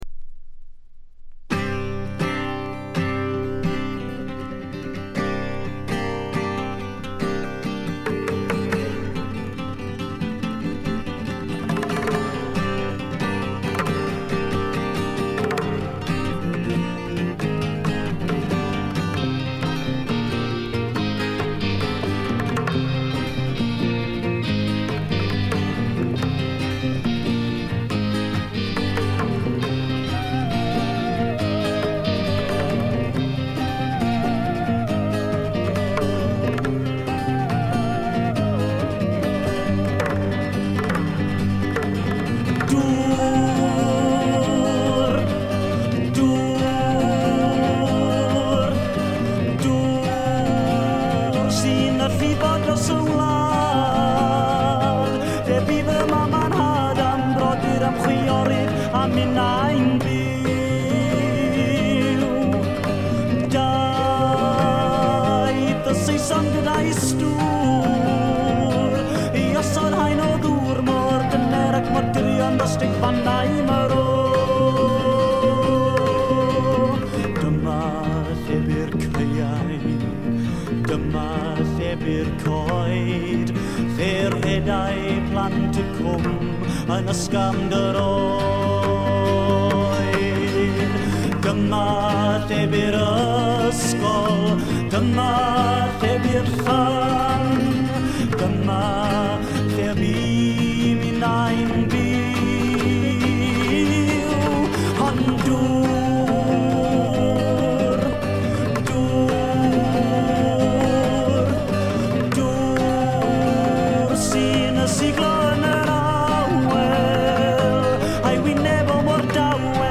内容はフォーク、アシッド、ポップ、ロックと様々な顔を見せる七変化タイプ。
試聴曲は現品からの取り込み音源です。